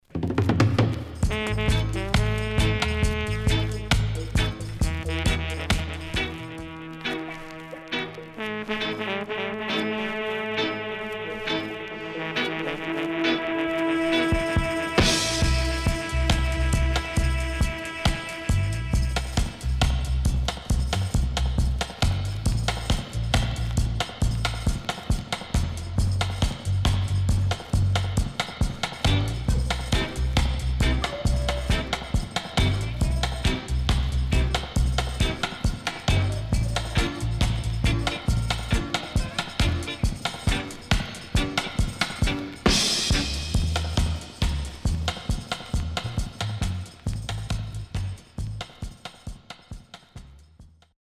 HOME > Back Order [VINTAGE 7inch]  >  STEPPER  >  INST 70's
SIDE A:序盤プレス起因による強めヒスノイズ入ります。